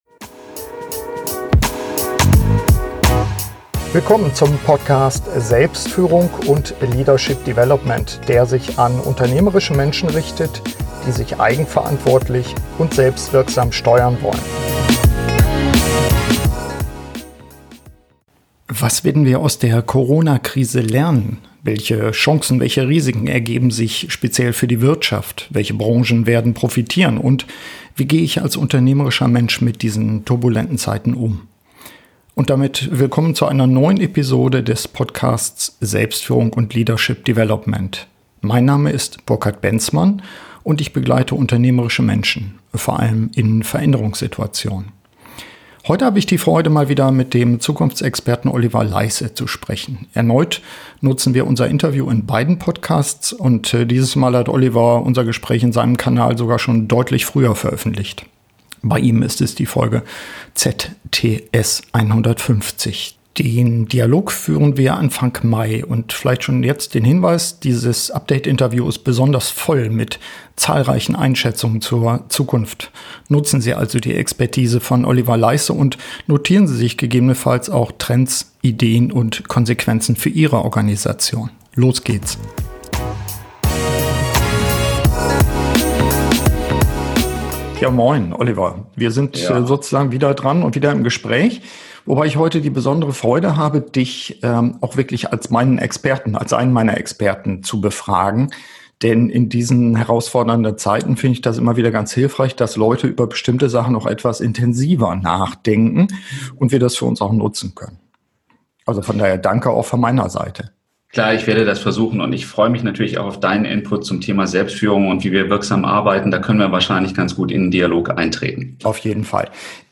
Total prallvolles Update-Interview